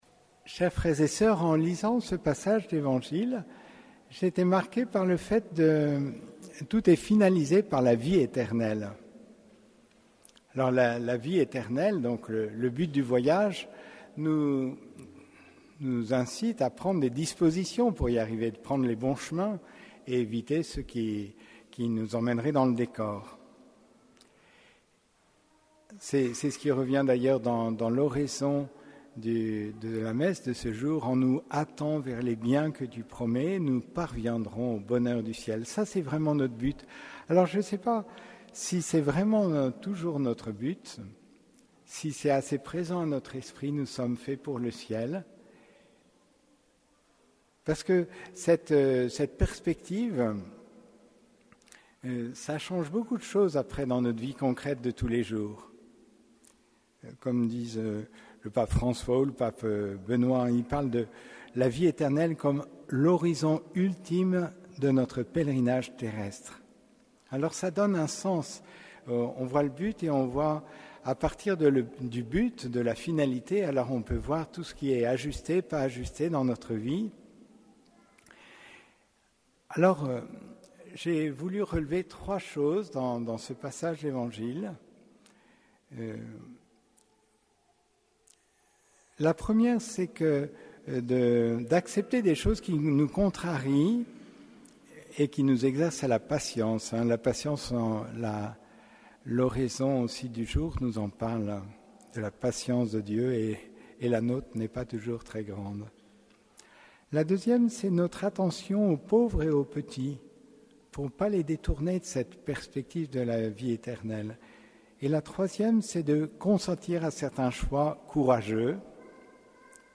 Homélie du 26e dimanche du Temps Ordinaire